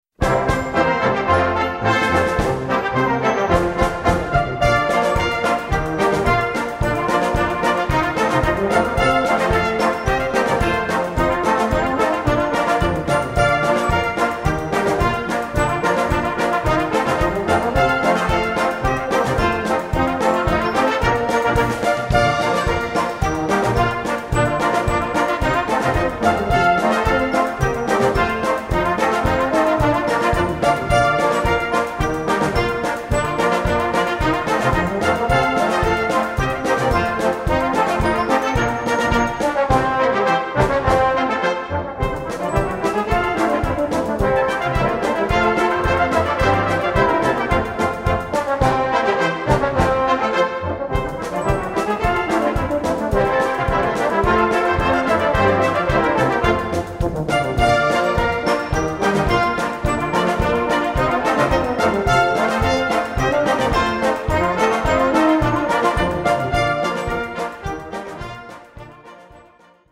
Komponist: Volksweise
2:45 Minuten Besetzung: Blasorchester PDF